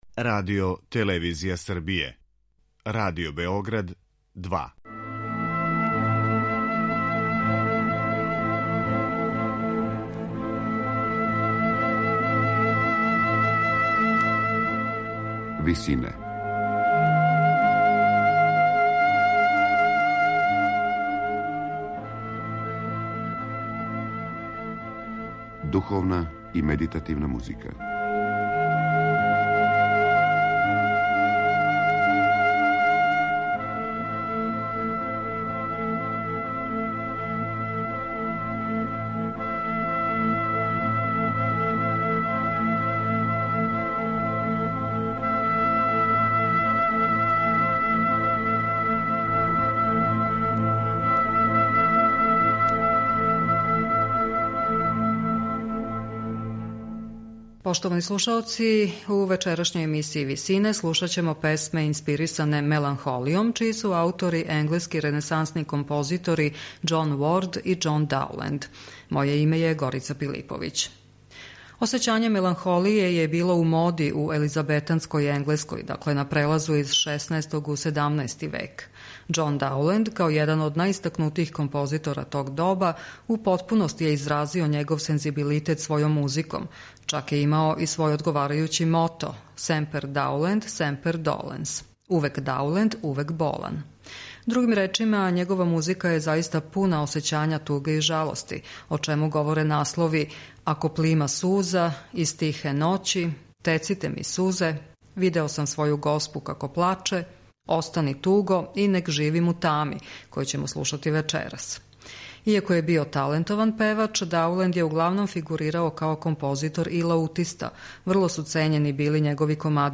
песме инспирисане меланхолијом
енглески ренесансни композитори